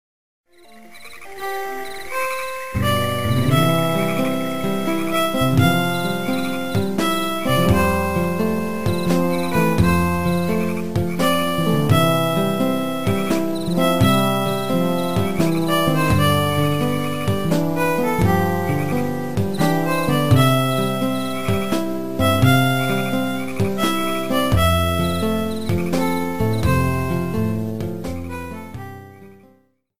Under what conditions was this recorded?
Reduced length to 30 seconds, with fadeout. This is a sample from a copyrighted musical recording.